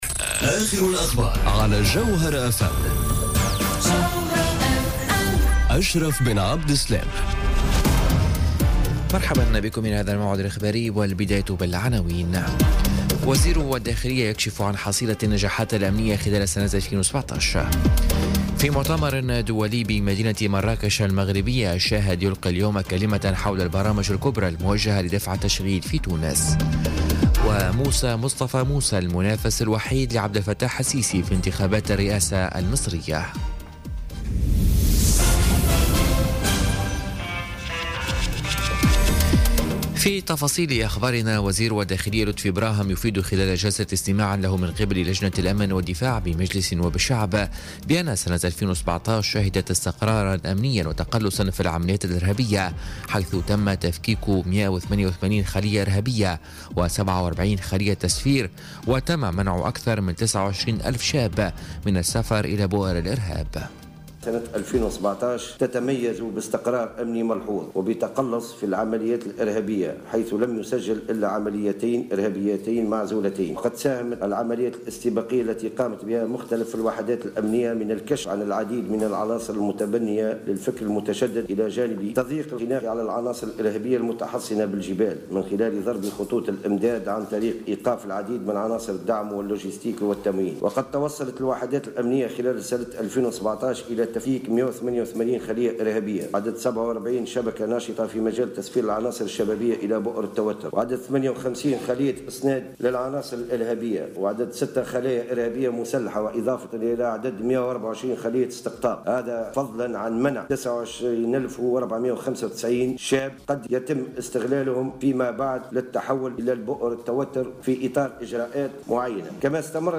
نشرة أخبار منتصف الليل ليوم الثلاثاء 30 جانفي 2018